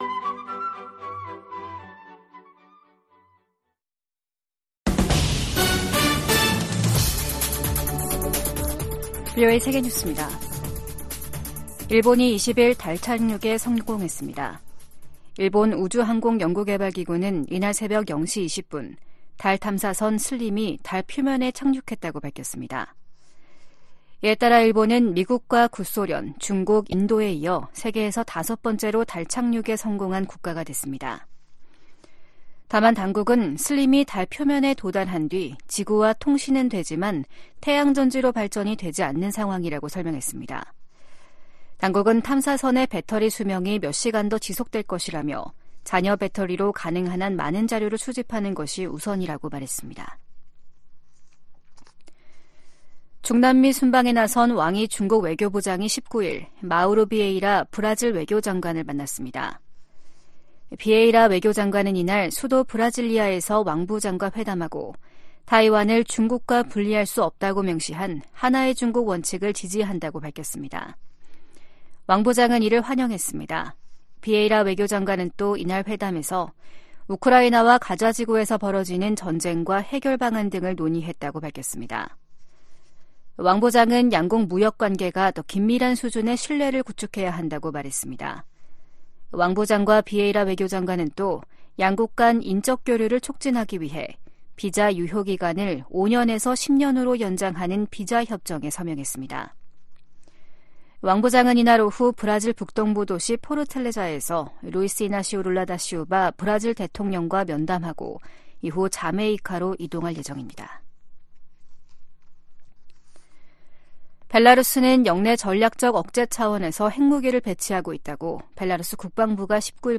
VOA 한국어 아침 뉴스 프로그램 '워싱턴 뉴스 광장' 2024년 1월 20일 방송입니다. 북한이 수중 핵무기 체계 '해일-5-23'의 중요 시험을 동해 수역에서 진행했다고 발표했습니다. 유엔 안전보장이사회가 새해 들어 처음 북한 관련 비공개 회의를 개최한 가운데 미국은 적극적인 대응을 촉구했습니다. 최근 심화되는 북러 군사협력으로 향후 10년간 북한의 역내 위협 성격이 급격하게 바뀔 수 있다고 백악관 고위 당국자가 전망했습니다.